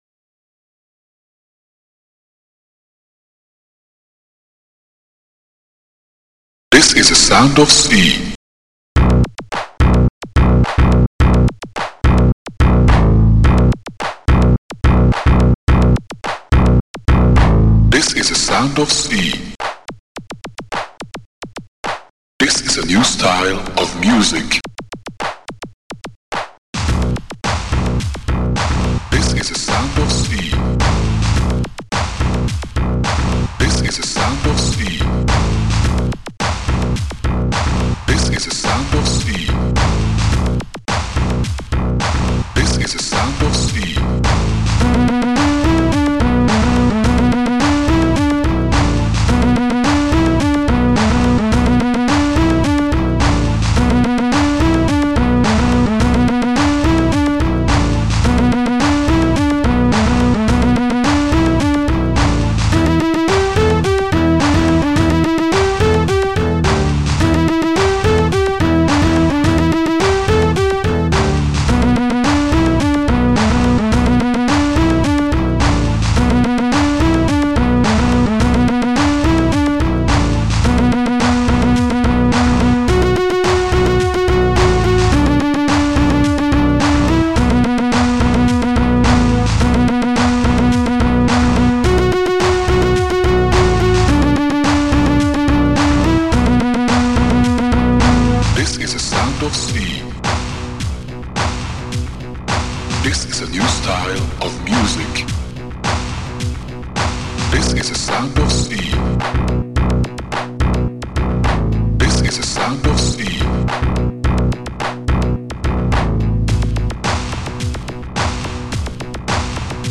Protracker and family
st-04:ANIMATE-CLAP
st-67:slapbass2
st-67:syntbrass